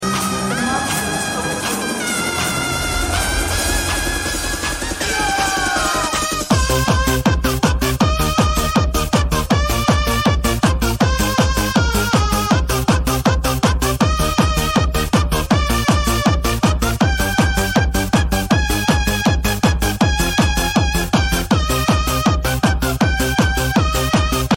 ad yang bertanya bunyi ekzos sound effects free download